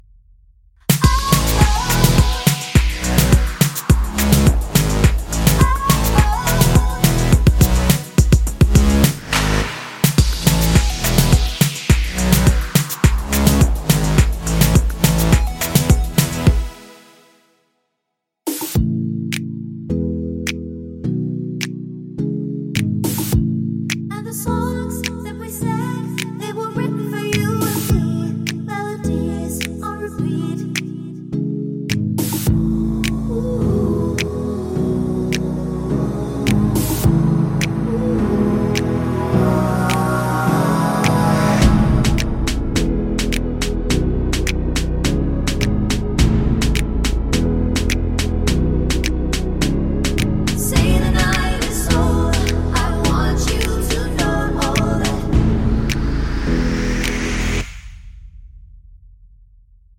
no Backing Vocals But With Aahs Pop (2010s) 3:53 Buy £1.50